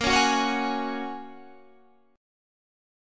Bbm7#5 chord